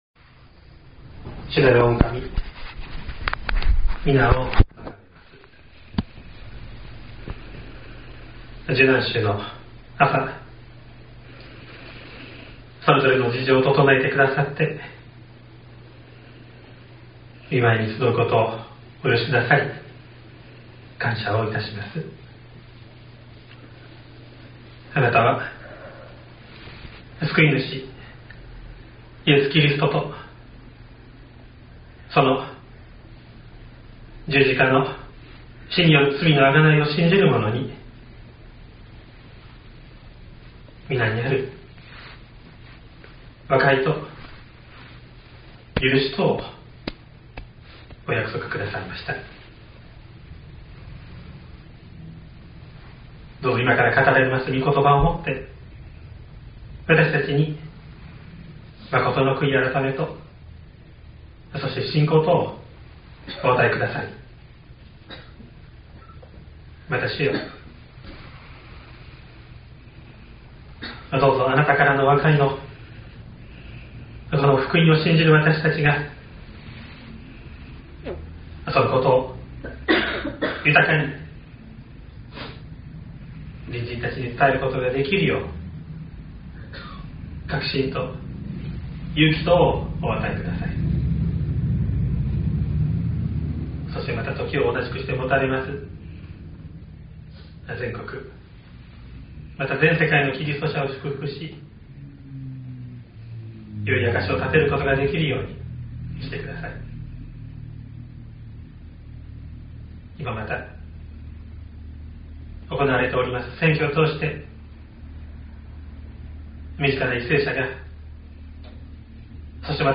2023年04月02日朝の礼拝「立ち去られたイエス」西谷教会
説教アーカイブ。